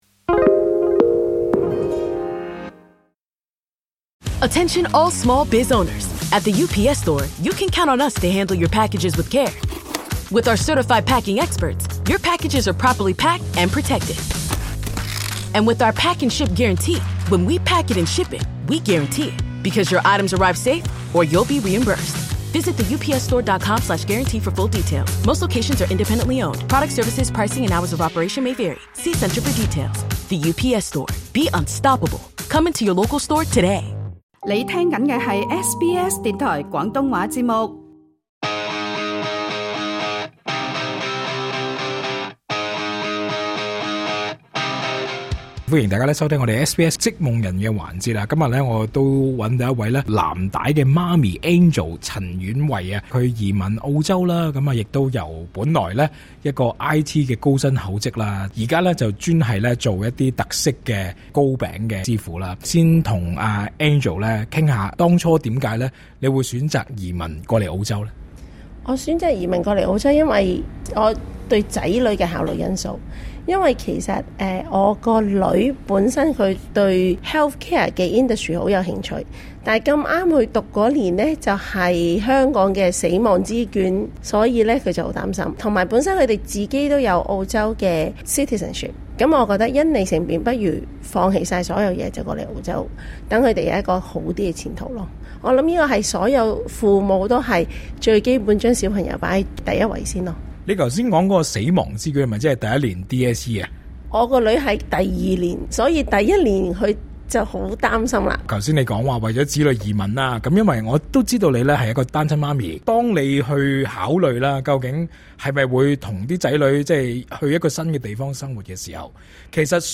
詳盡訪問：